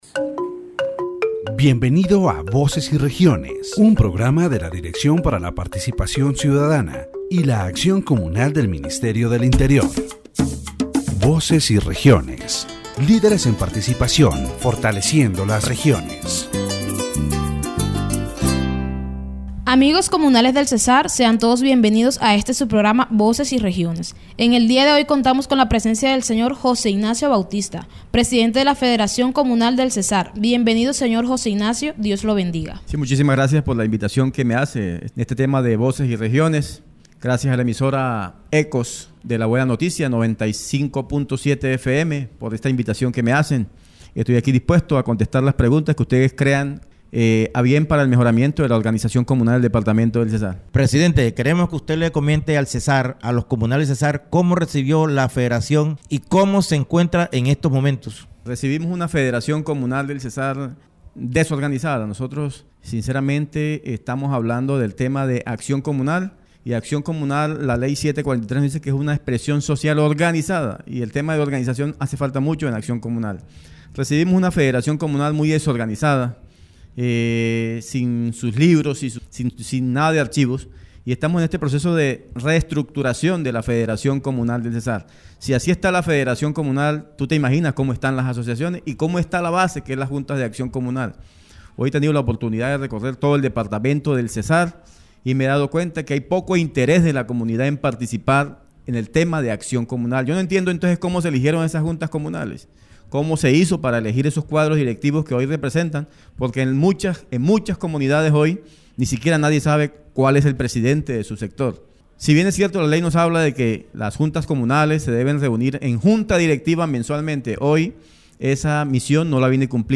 The radio program "Voces y Regiones" addressed the problem of communal action in the department of César, Colombia.